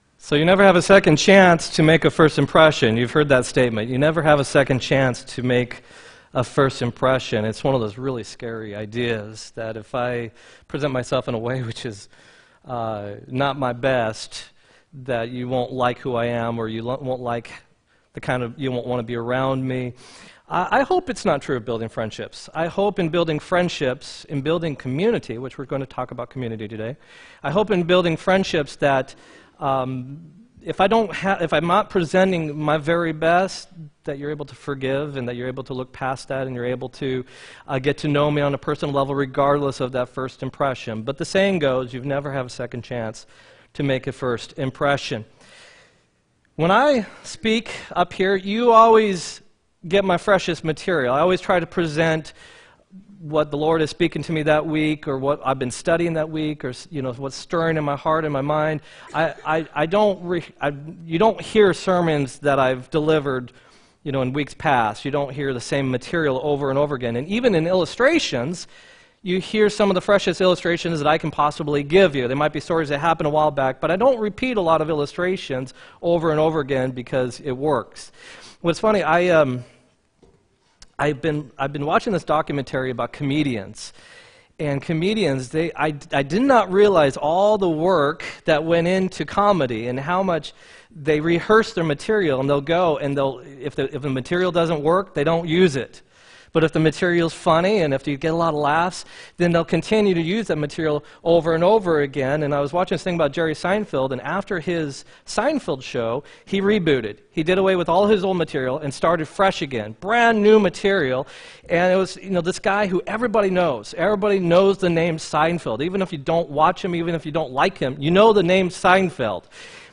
1-6-18 sermon
1-6-18-sermon.m4a